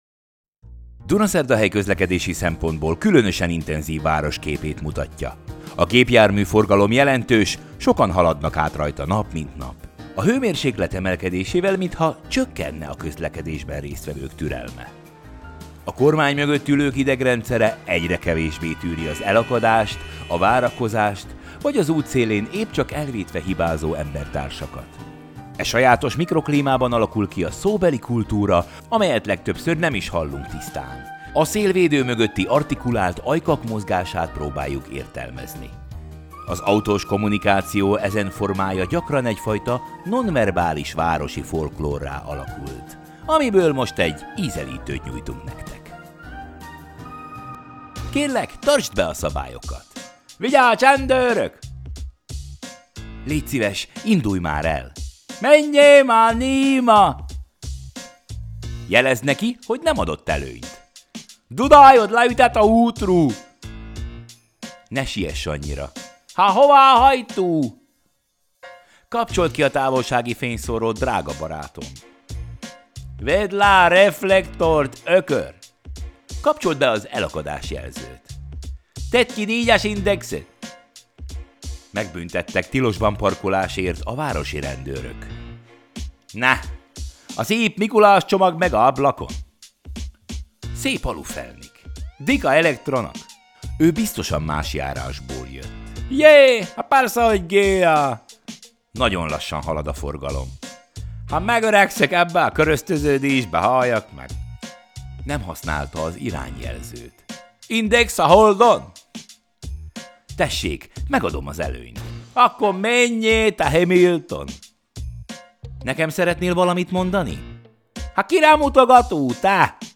Come Back – Dope West Coast Hip Hop 2018 Instrumental